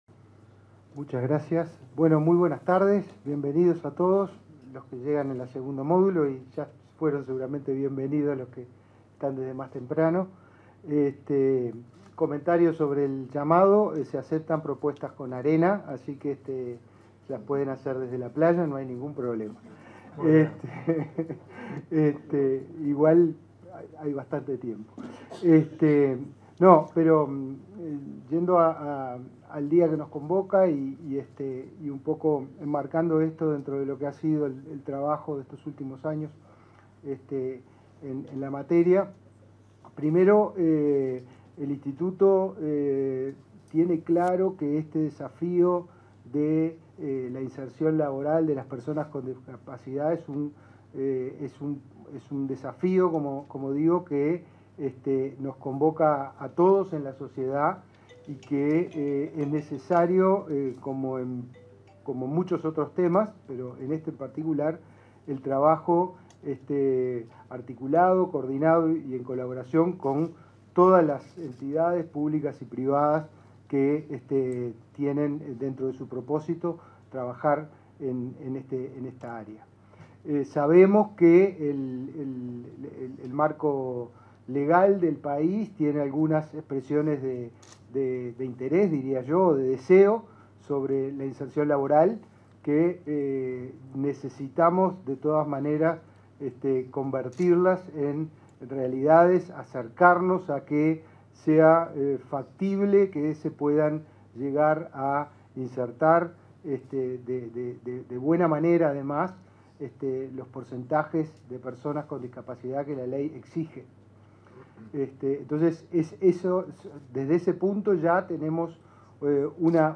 Acto de lanzamiento del programa de inclusión para la empleabilidad de personas con discapacidad
Acto de lanzamiento del programa de inclusión para la empleabilidad de personas con discapacidad 14/12/2023 Compartir Facebook X Copiar enlace WhatsApp LinkedIn En el marco del lanzamiento de un programa para fomentar oportunidades laborales a personas con discapacidad, este 14 de diciembre, se expresaron el ministro de Desarrollo Social, Martín Lema y el director de Inefop, Pablo Darscht.